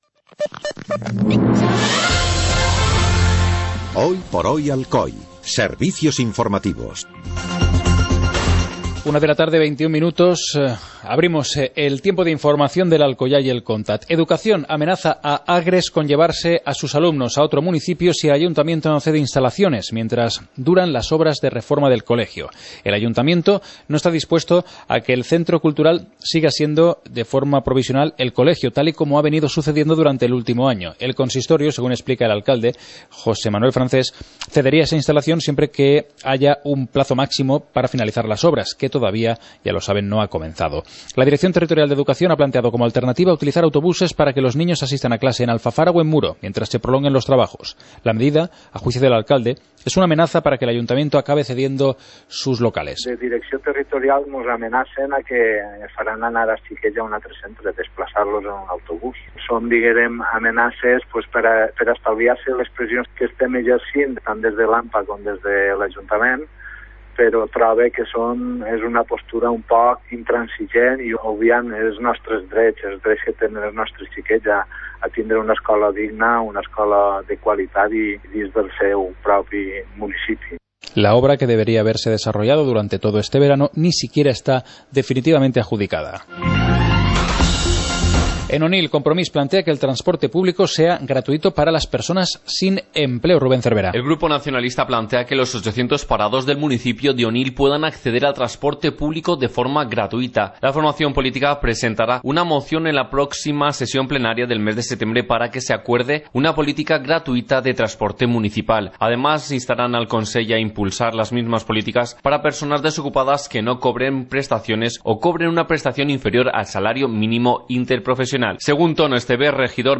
Informativo comarcal - miércoles, 27 de agosto de 2014